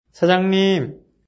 発音
사장님 [サジャン二ム]